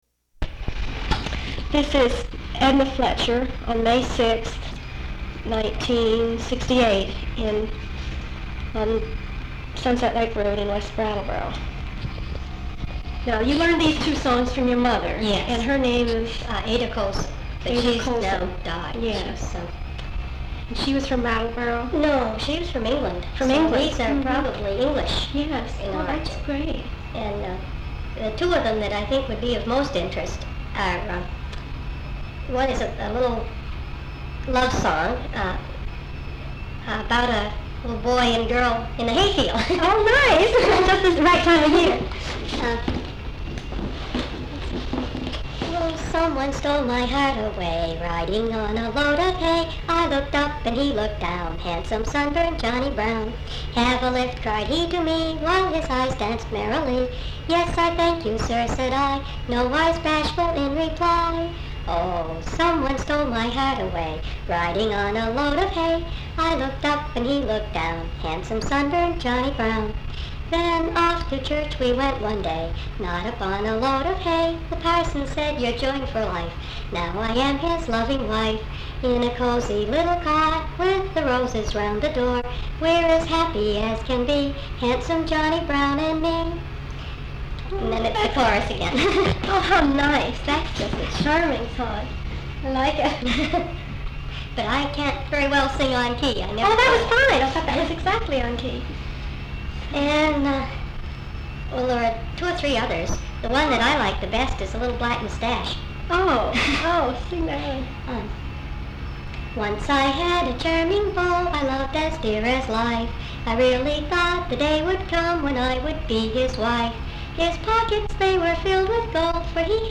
Folk songs, English--Vermont